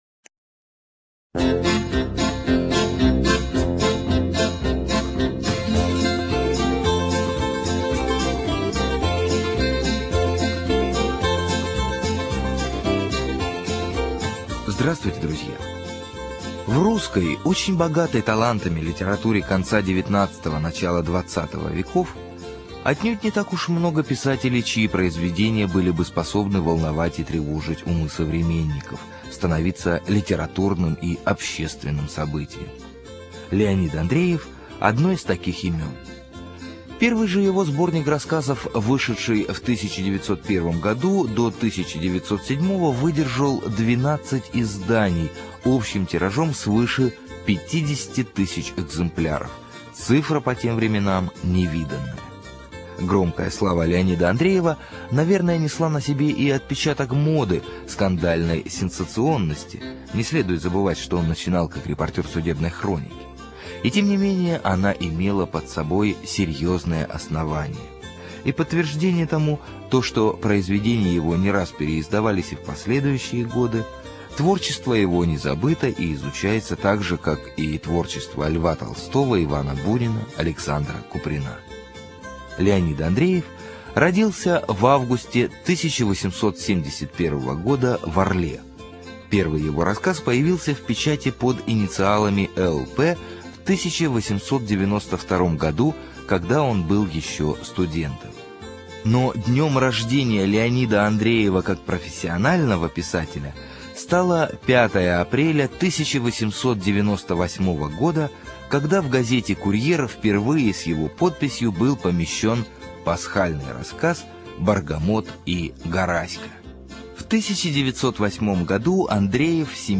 ЖанрКлассическая проза
Студия звукозаписиРадио 4